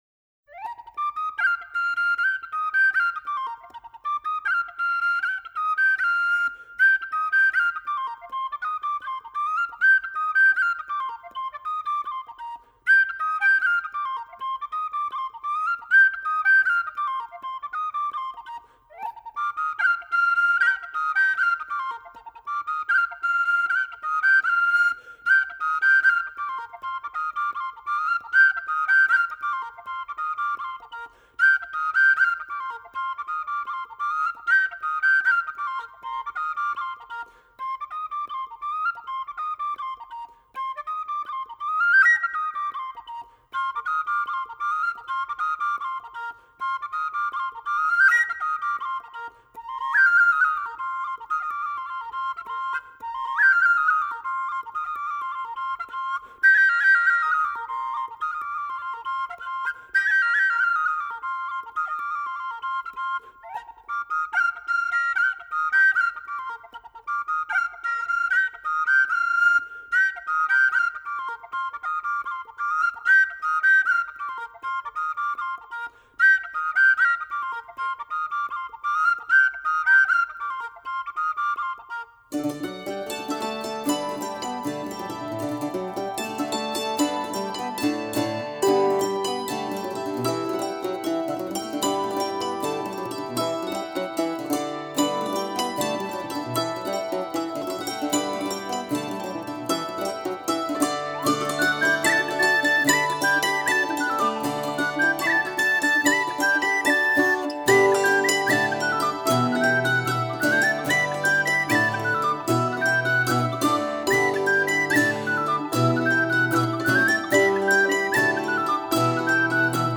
Genre: Traditional.